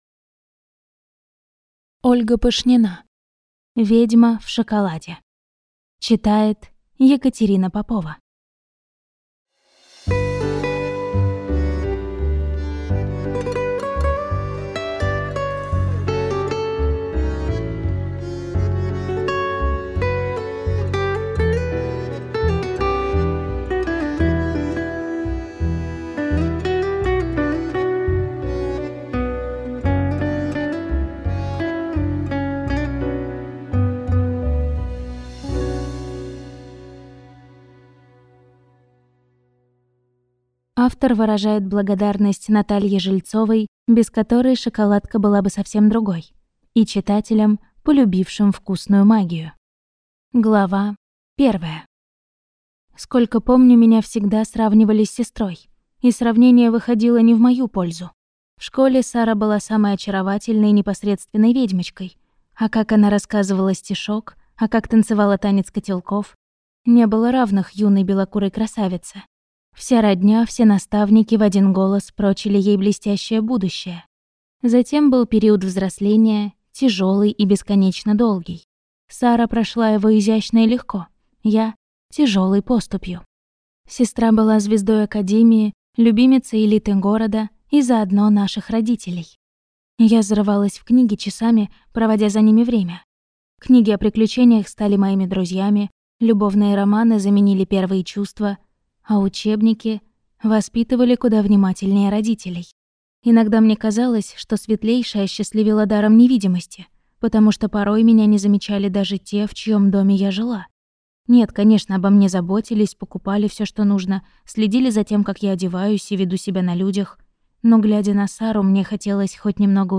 Аудиокнига Ведьма в шоколаде - купить, скачать и слушать онлайн | КнигоПоиск